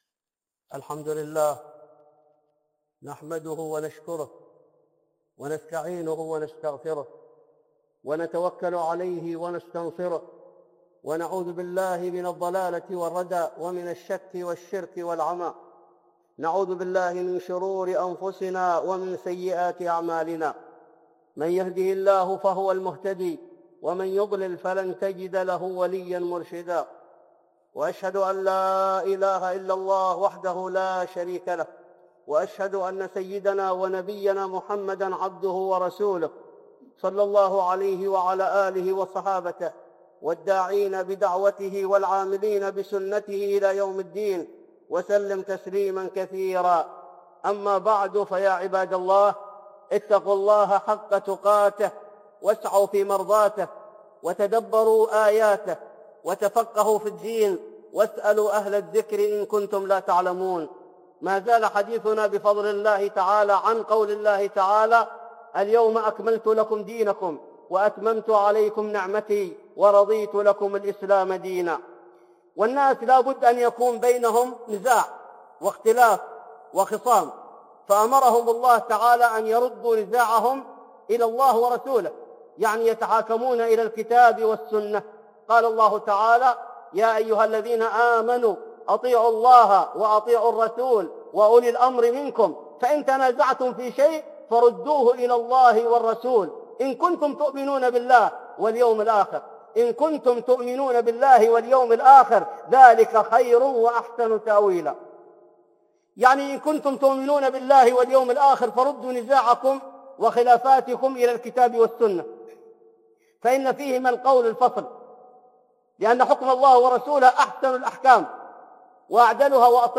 (خطبة جمعة) الدين الكامل وبيان أن السياسة جزء من الإسلام 2